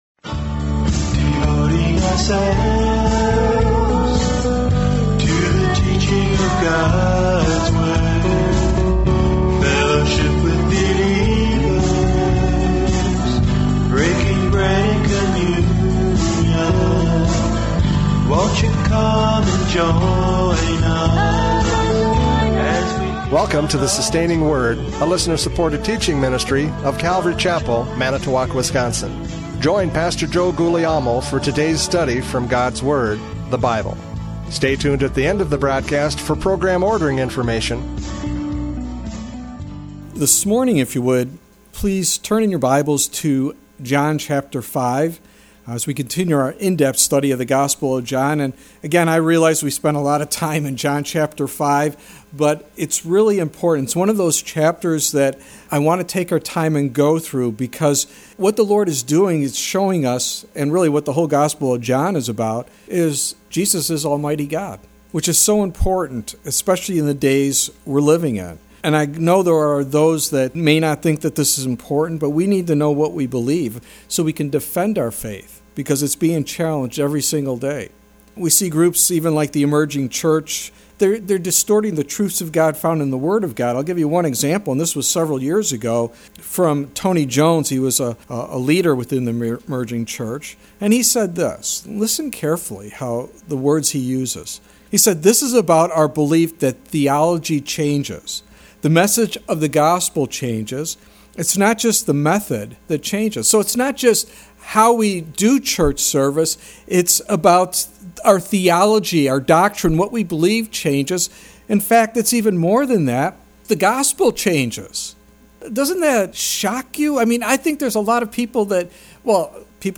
John 5:37-38 Service Type: Radio Programs « John 5:36 Testimony of Miracles!